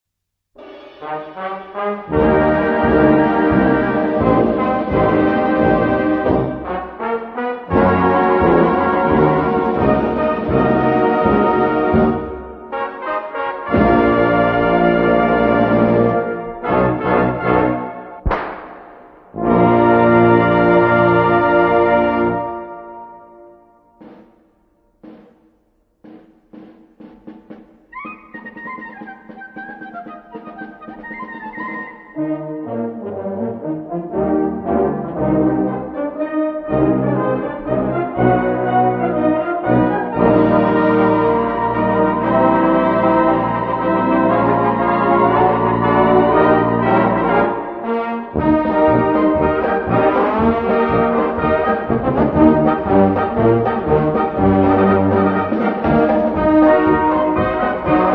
Gattung: Western Story
Besetzung: Blasorchester